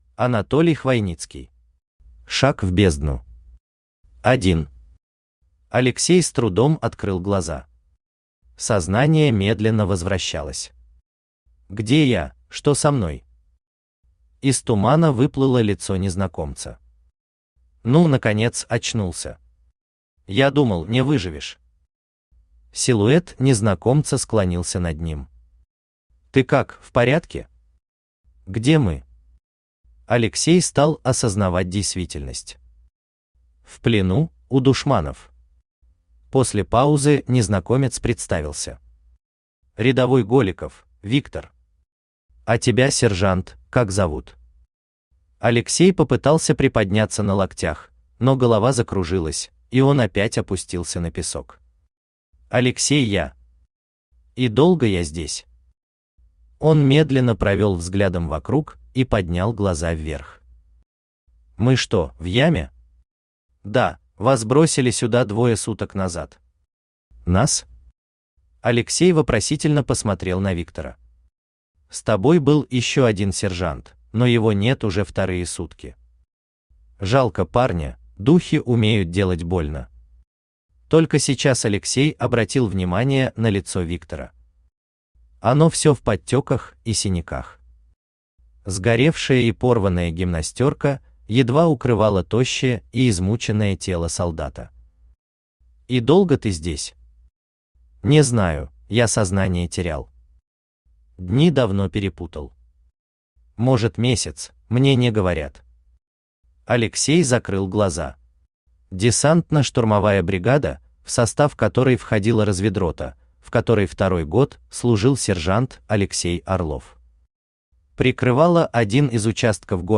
Аудиокнига Шаг в бездну | Библиотека аудиокниг
Aудиокнига Шаг в бездну Автор Анатолий Хвойницкий Читает аудиокнигу Авточтец ЛитРес.